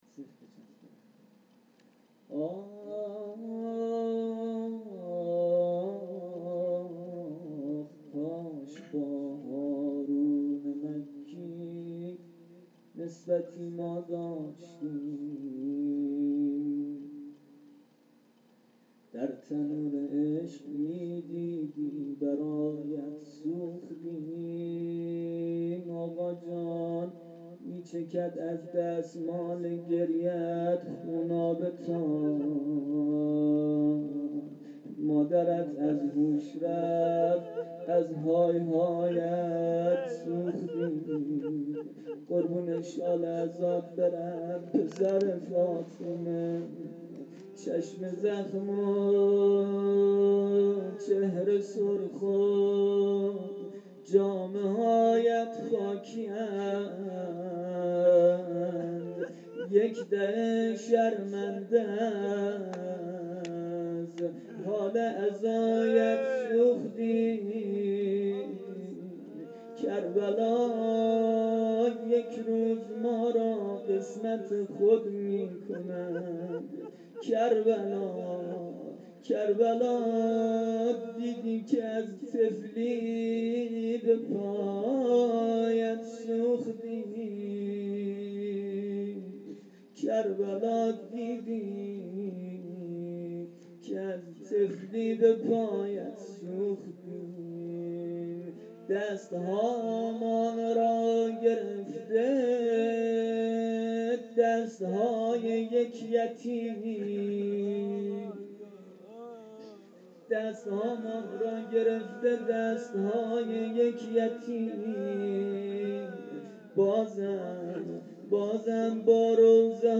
روضه شب ششم محرم 93